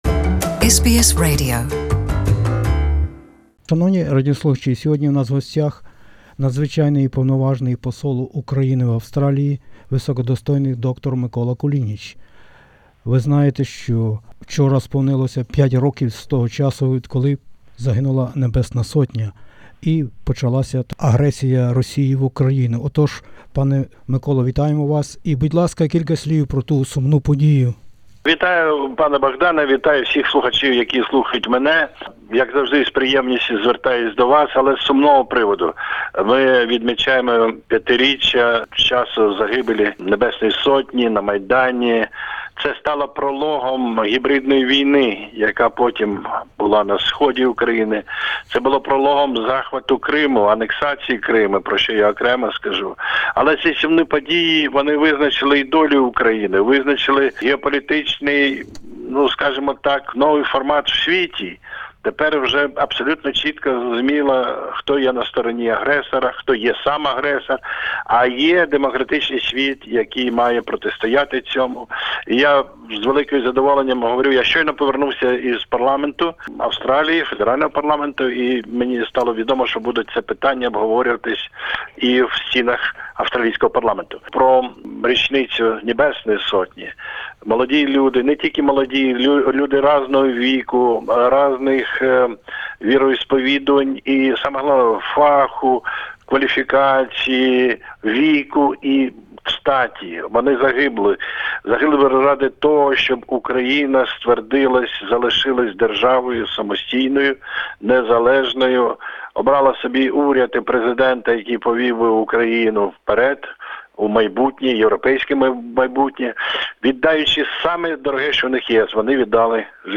interviewed Dr Mykola Kulinich, Ambassador of Ukraine to Australia from 2015. We spoke about the fifth anniversary of the Revolution of Dignity and paid Respects to the Heavenly Hundred Heroes in Kyiv.